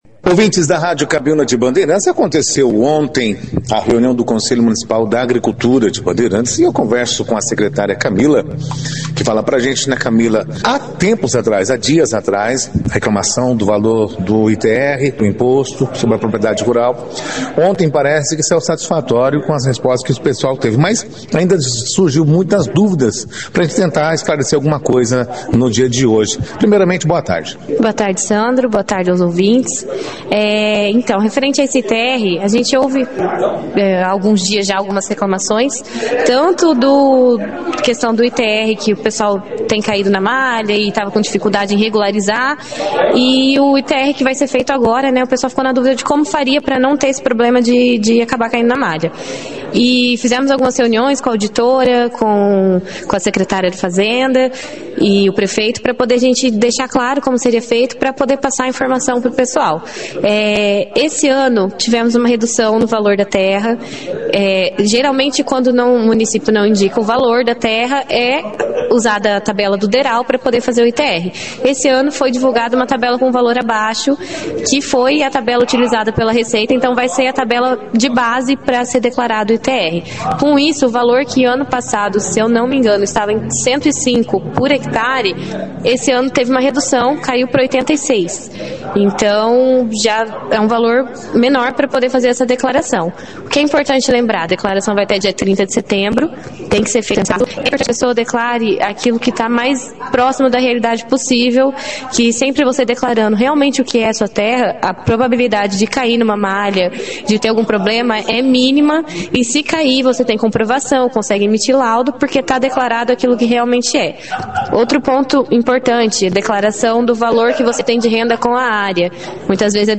A reunião foi destaque na 2ª edição do Jornal Operação Cidade desta quinta-feira, 14 de agosto